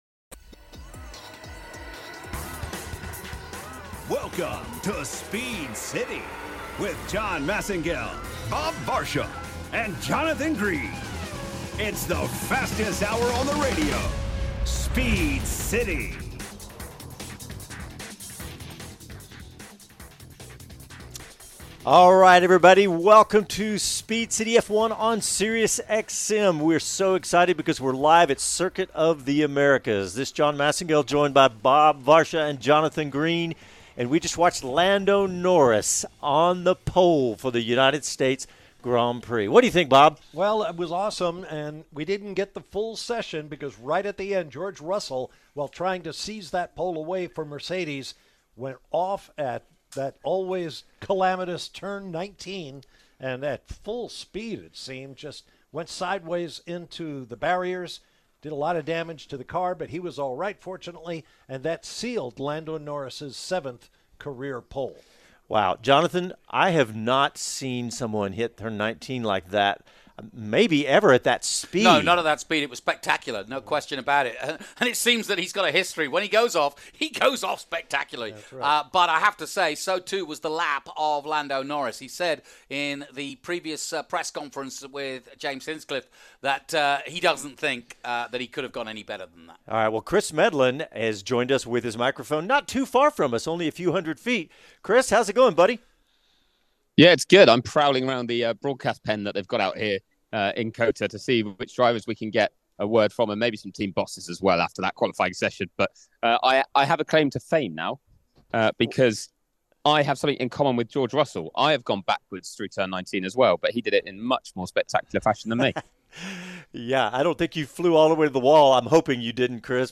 Listen to the LIVE SiriusXM radio broadcast of the Speed City F1 United States Grand Prix post-qualifying show.